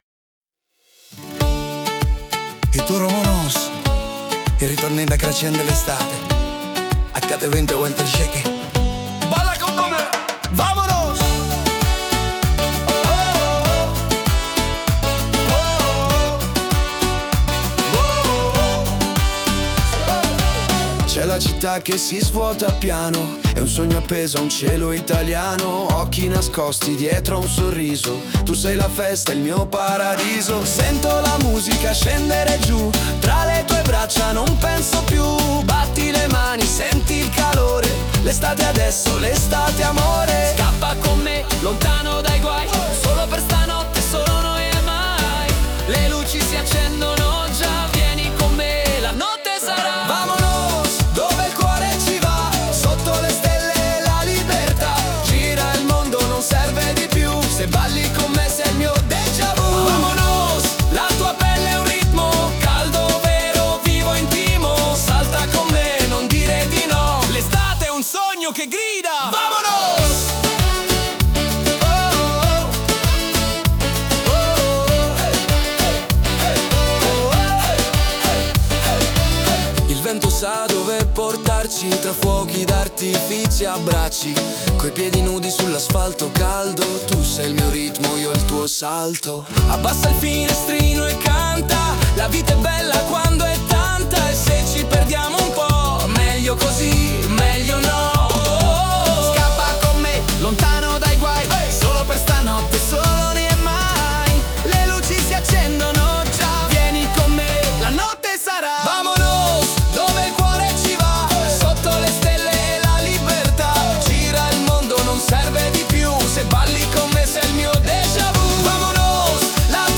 Latino